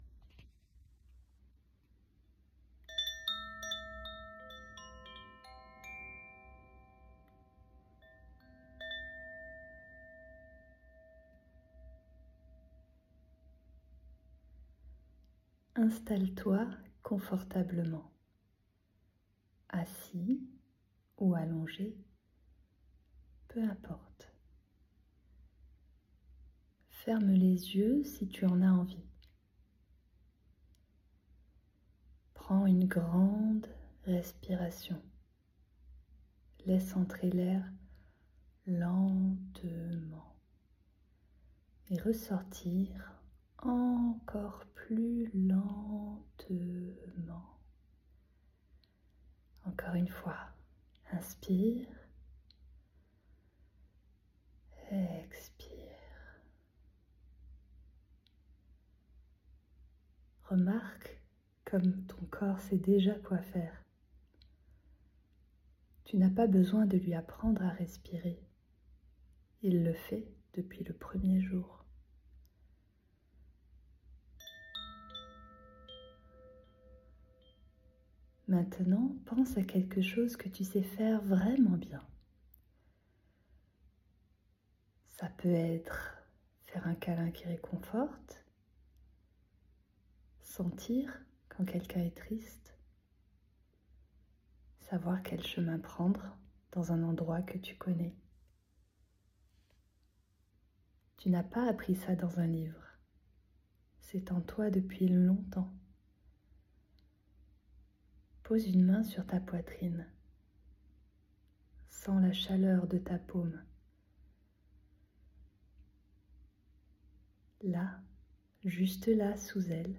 Tu sais déjà – méditation guidée Bonne nuit mon ange mars 2026